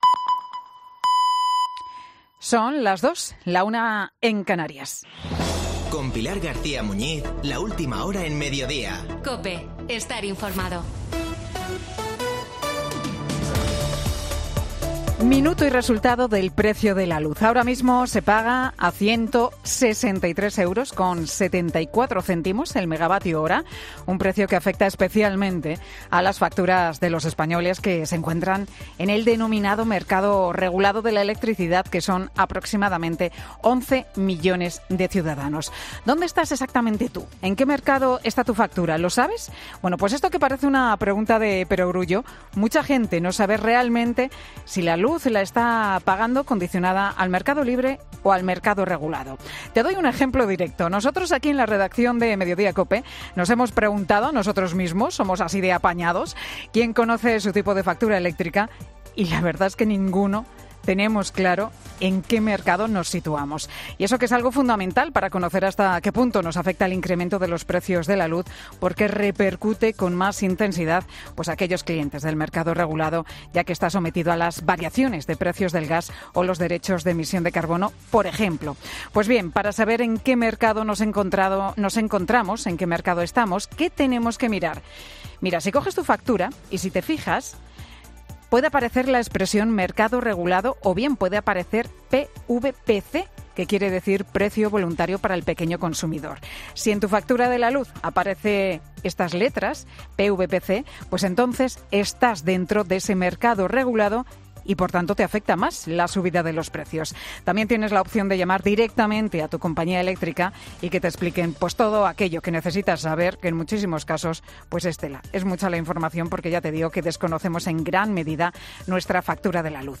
Escuchar Boletín Boleto de noticias COPE del 14 de septiembre 2021 a las 14.00 horas Leer más Redacción Digital 14 sep 2021, 14:00 Descargar Facebook Twitter Whatsapp Telegram Enviar por email Copiar enlace